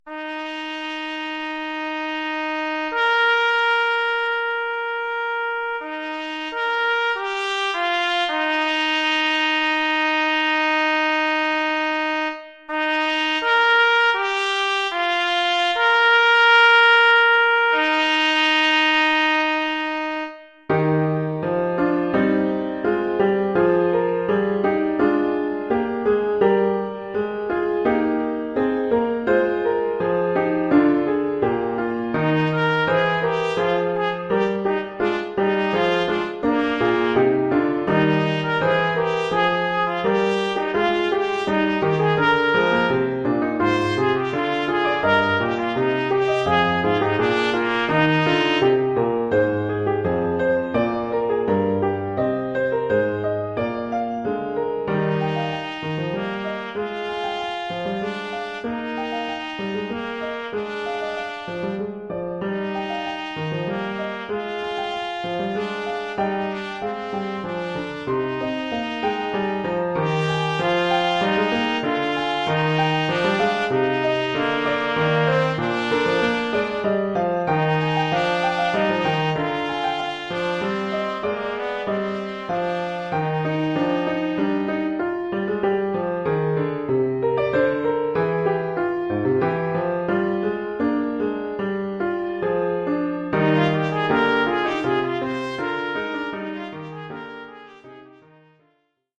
Oeuvre pour trompette ou cornet
ou bugle et piano.
Niveau : débutant.